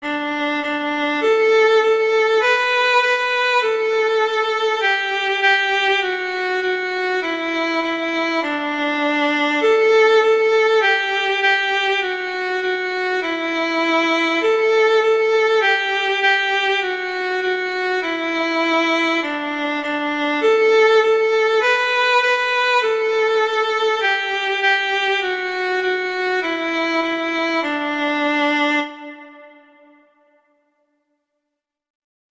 arrangements for violin solo
classical, children